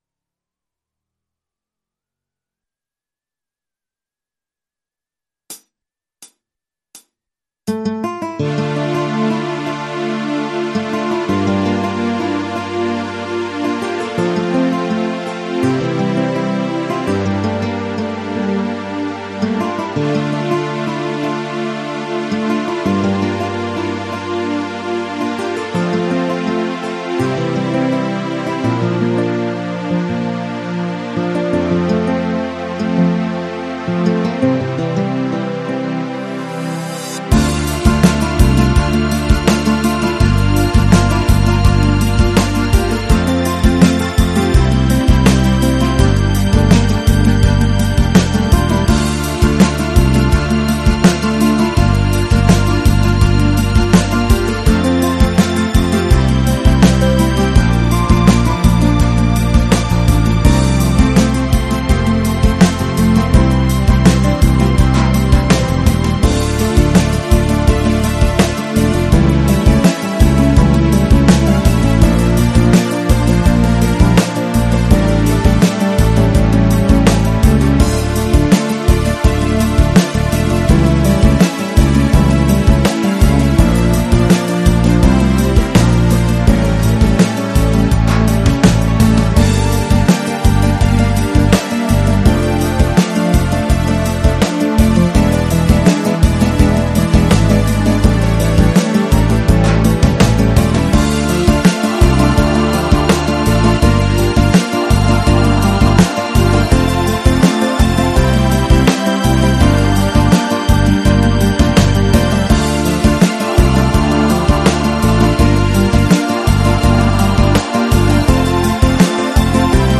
version instrumentale multipistes